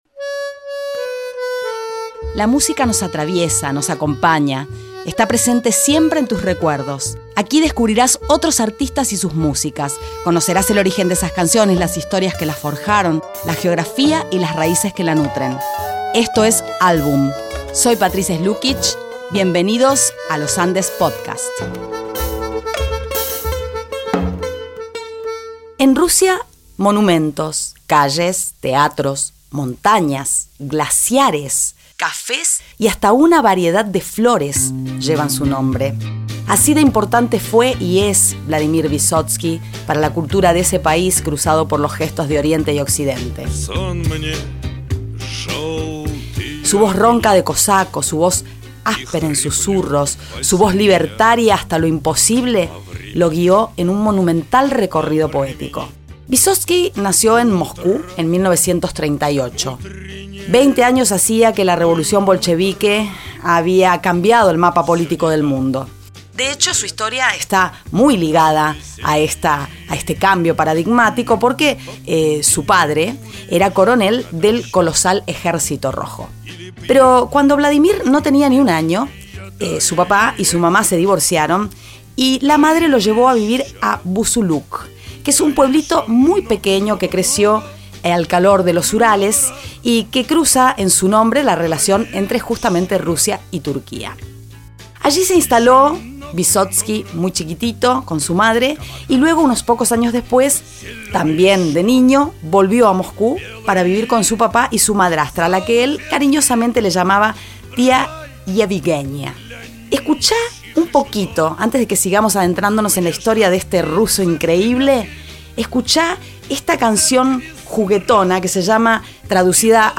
Te presentamos en este episodio a uno de los músicos, actores y directores más importantes de la época de la Unión Soviética, en Rusia. La voz de cosaco de este artista ineludible pudo con el régimen y vive hoy en el corazón del pueblo.